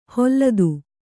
♪ holladu